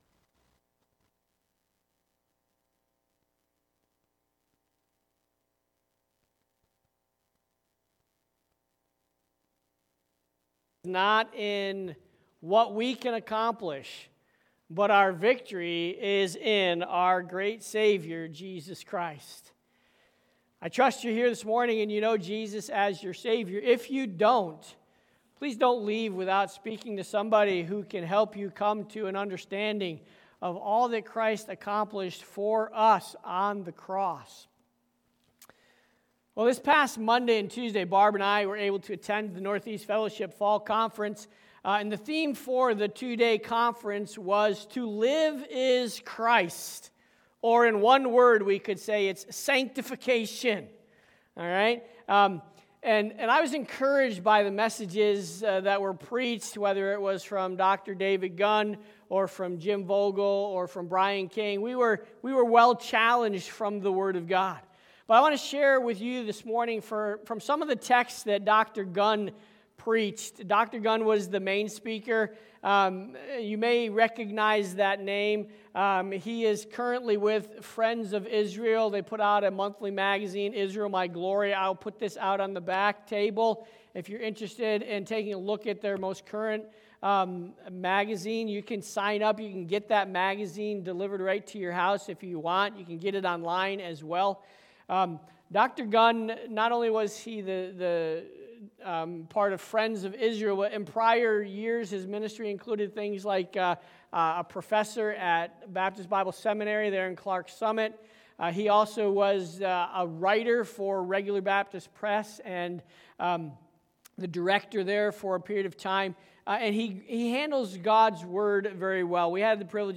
Sermons by CBCP